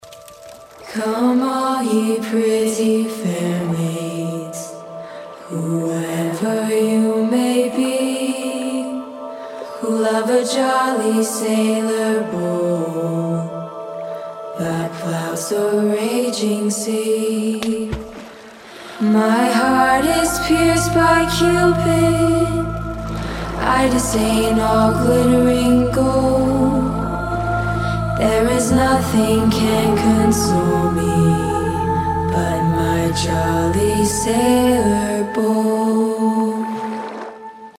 • Качество: 192, Stereo
женский вокал
OST
красивый женский вокал
хор
акапелла